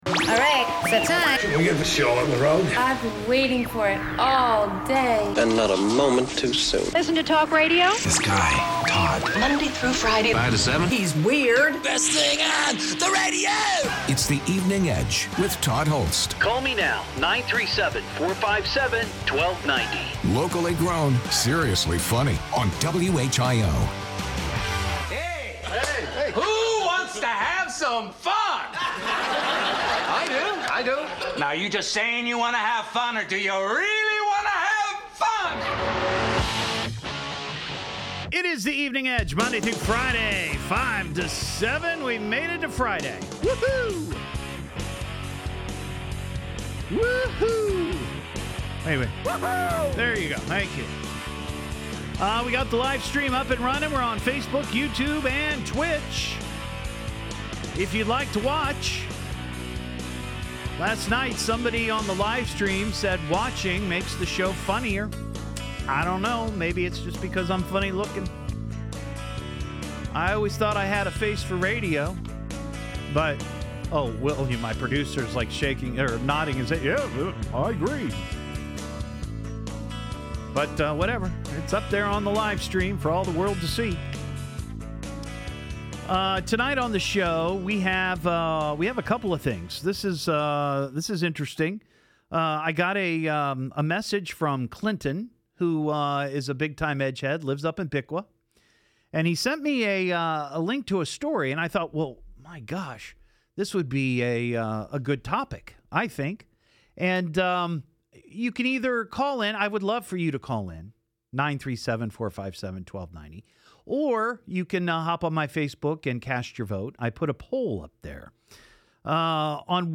Dayton Radio Interview (Skip to 18 minute mark)